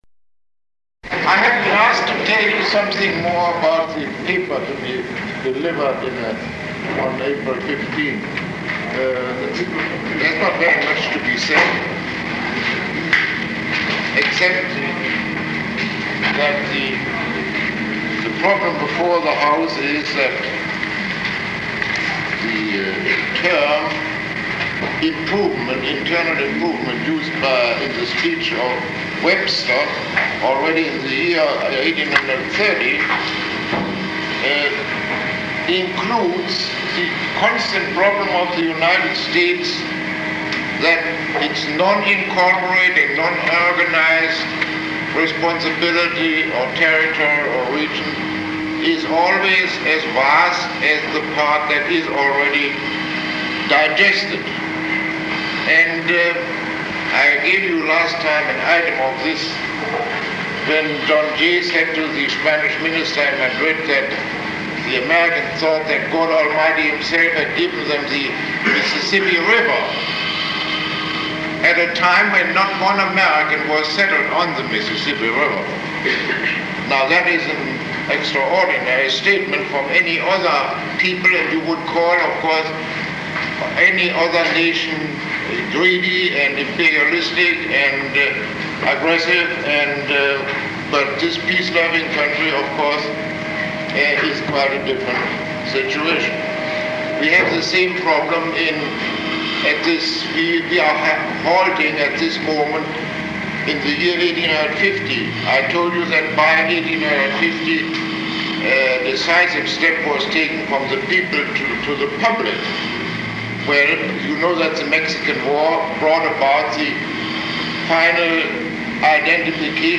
Lecture 19